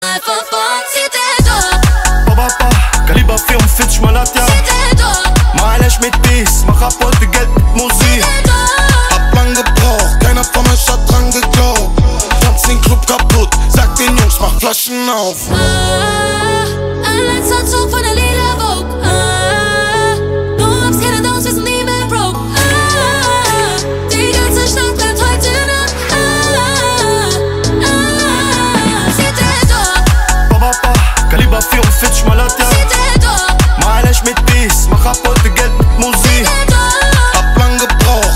Kategorien POP